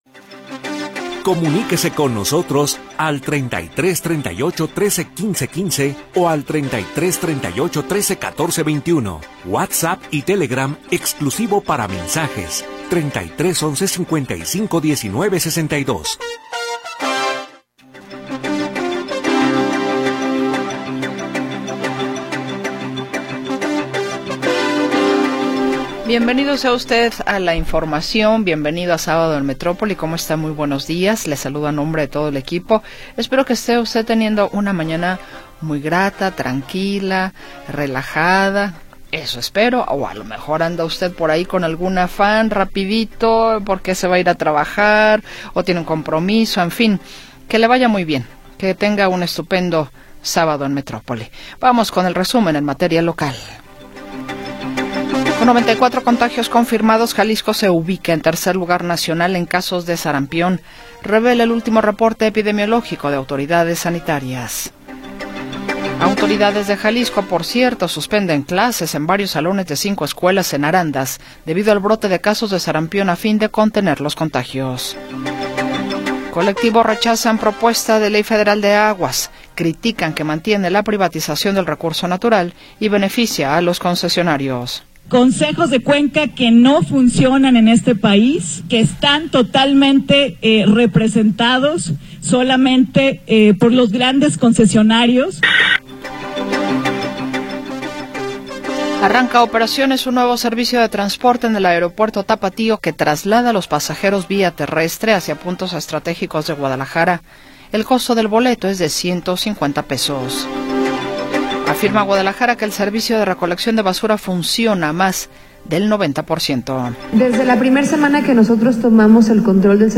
Segunda hora del programa transmitido el 18 de Octubre de 2025.